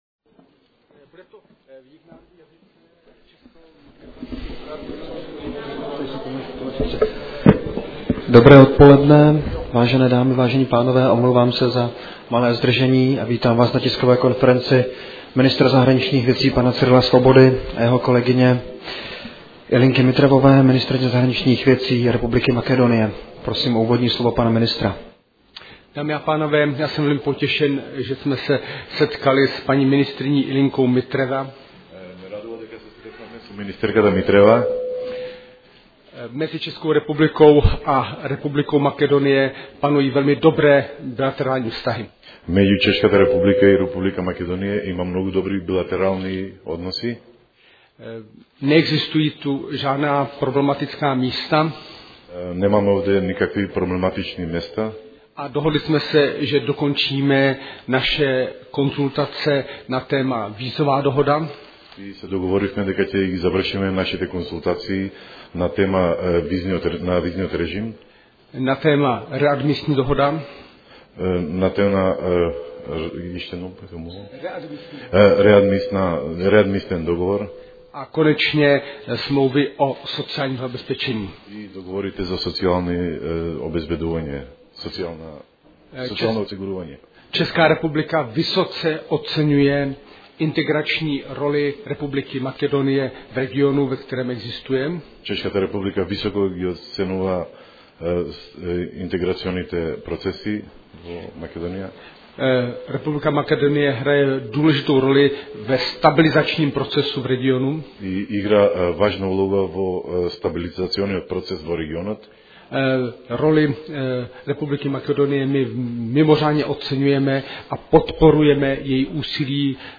Dne 4.října se setkala s ministrem zahraničních věcí Cyrilem Svobodou. Záznam press-foyer (16 kbps, mp3, 12000 kHz Mono)